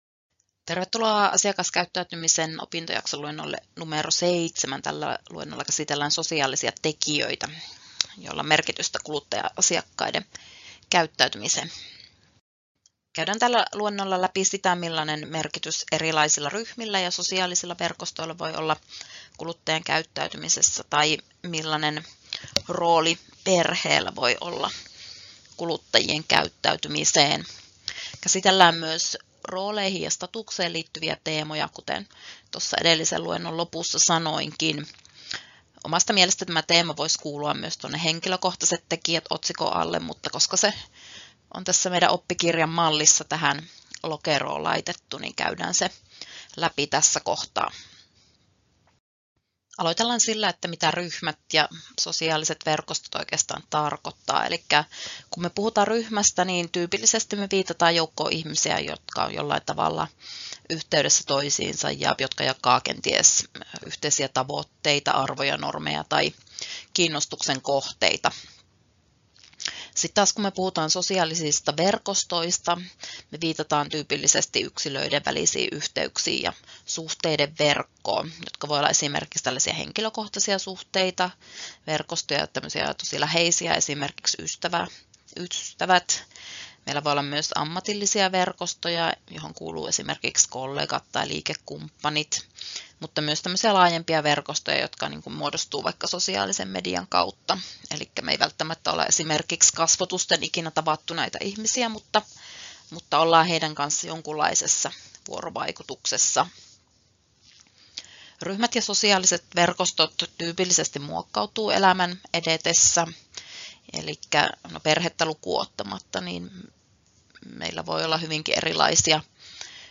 Luento 7: Sosiaaliset tekijät — Moniviestin